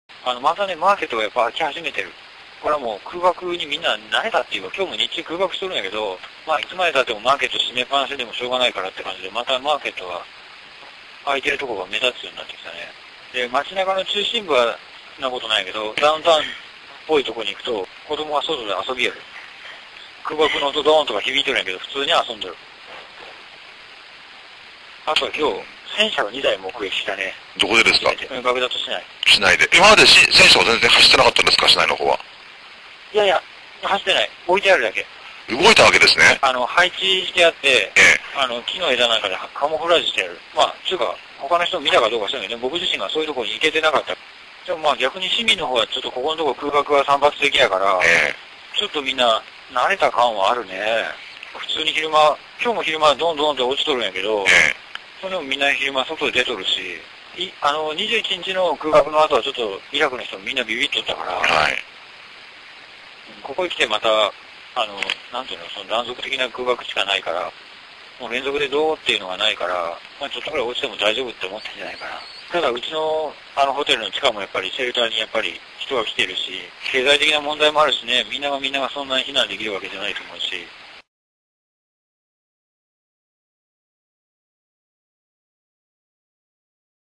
音声リポート